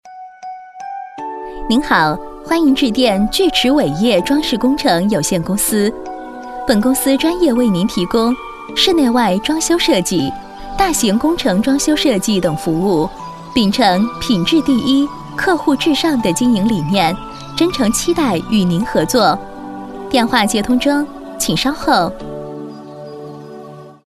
女26号
聚驰伟业装饰工程有限公司(彩铃)